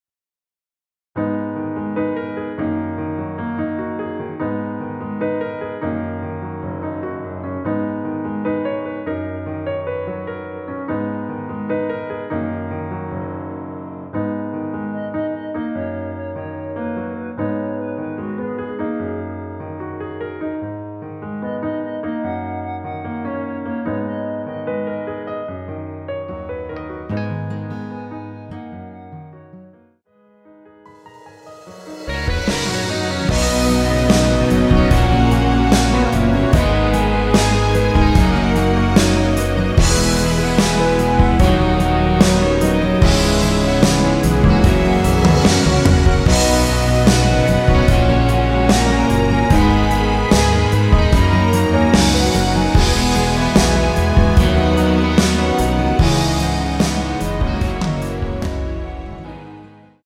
원키에서(-3)내린 멜로디 포함된 MR입니다.
Abm
앞부분30초, 뒷부분30초씩 편집해서 올려 드리고 있습니다.
중간에 음이 끈어지고 다시 나오는 이유는